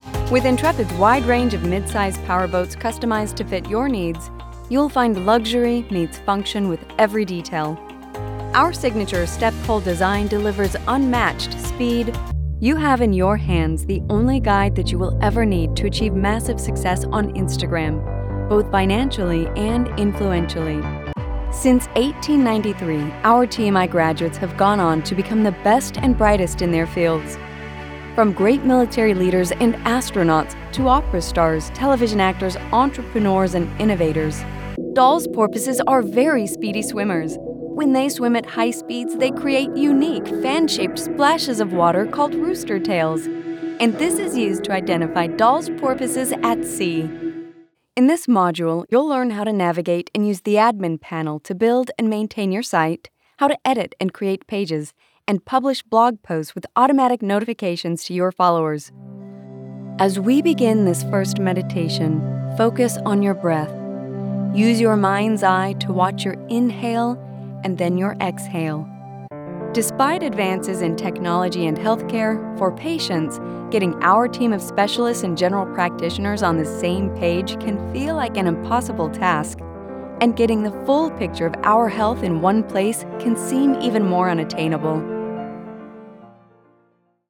Female, North American neutral accent, with an adult/young-adult vocal sound. Pitch is not too high and not too low, with a smooth vocal quality. Can be upbeat, happy and conversational or polished and professional depending on the read and desired tone.
Corporate Narration